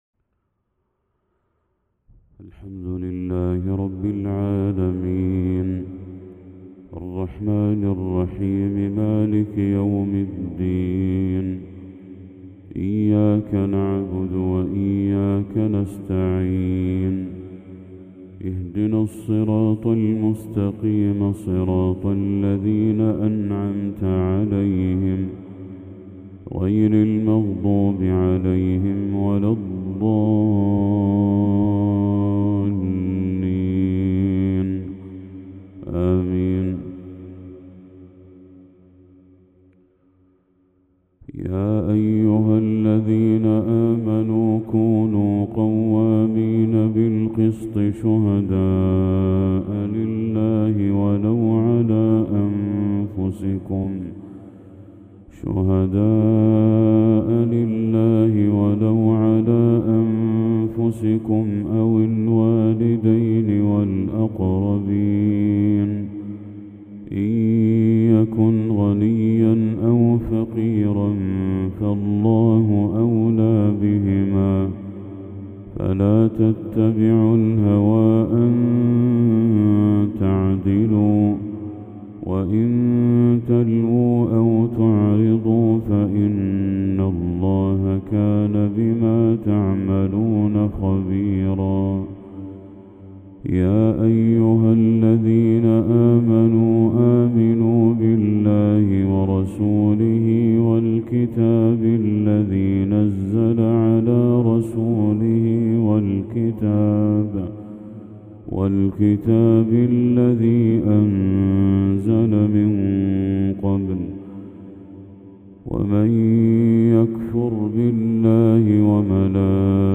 تلاوة جميلة من سورة النساء للشيخ بدر التركي | فجر 22 ذو الحجة 1445هـ > 1445هـ > تلاوات الشيخ بدر التركي > المزيد - تلاوات الحرمين